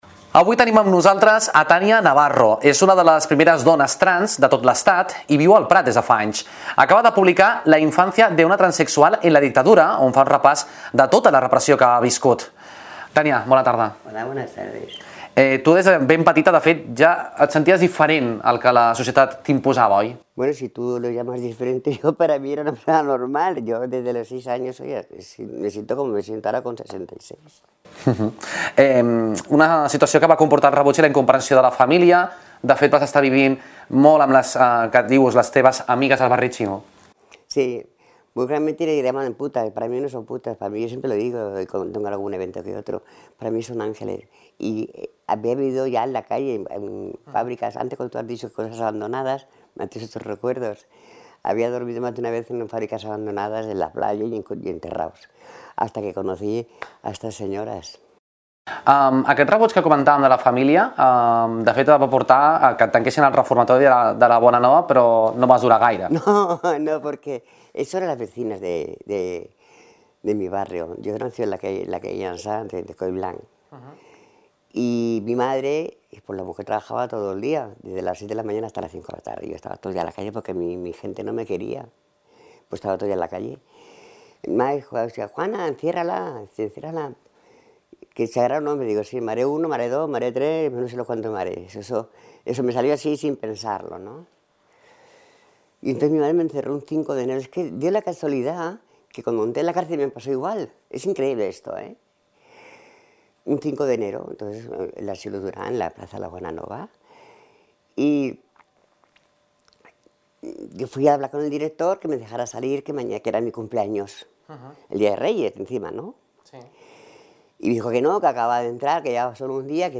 [ENTREVISTA]